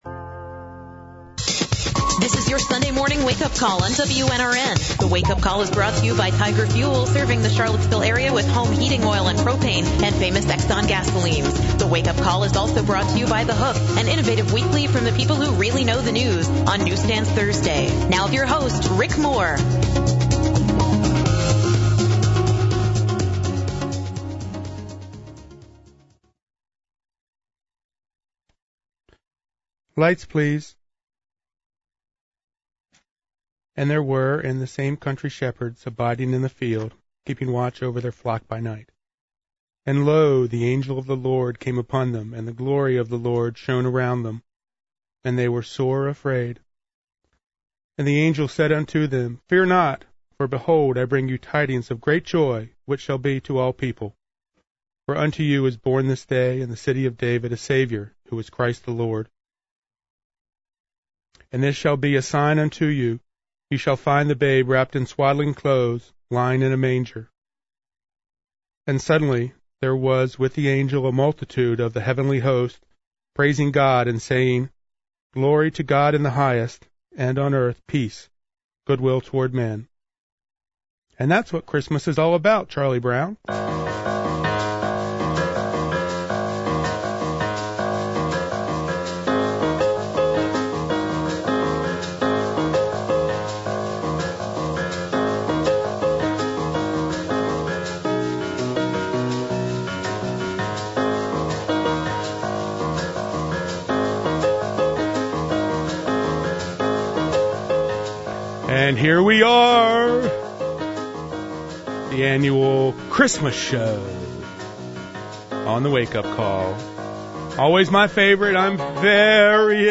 “Lake City” post-screening discussion